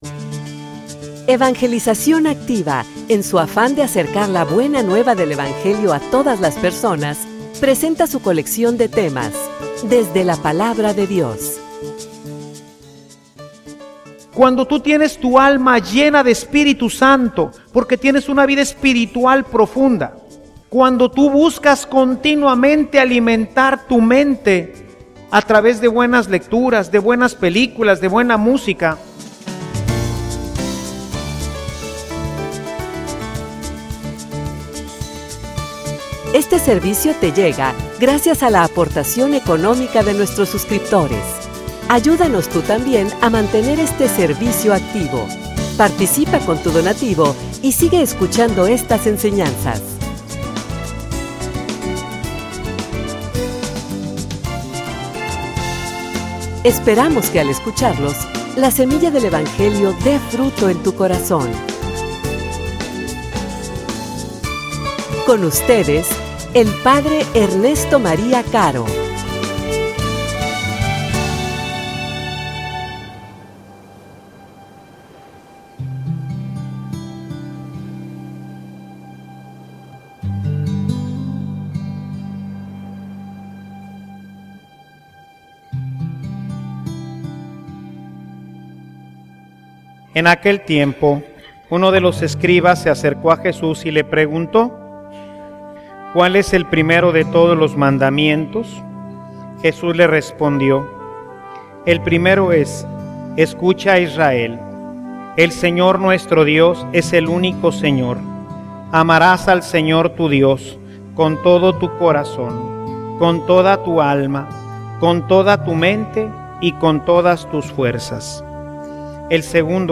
homilia_Amor_en_terminos_de_totalidad.mp3